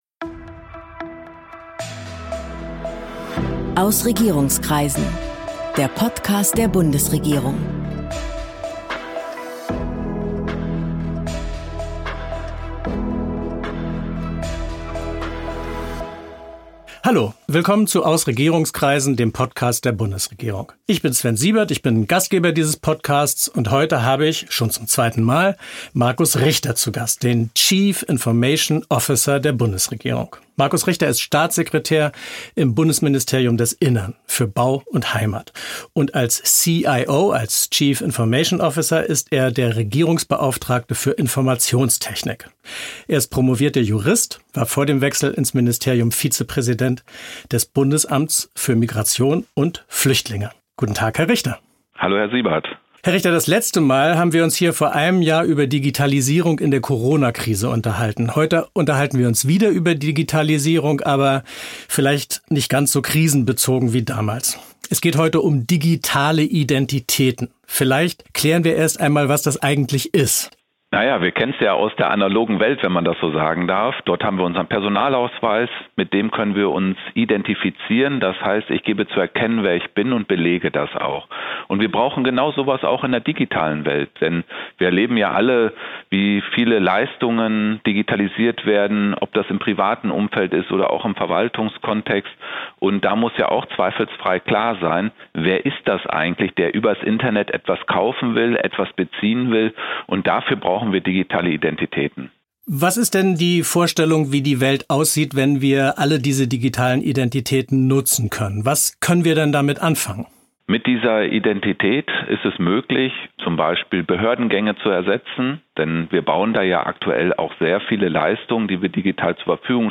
Die digitale Identität soll den Gang zur Behörde online ermöglichen, aber auch persönliche Daten sichern und beispielsweise beim Onlineshopping schützen. Markus Richter, Chief Information Officer des Bundes (CIO), spricht im Podcast über den elektronischen Personalausweis, die vielen Anwendungsmöglichkeiten, das enorme wirtschaftliche Potenzial sicherer digitaler Identitäten und den Datenschutz. Und er berichtet über den digitalen Impfausweis für Europa.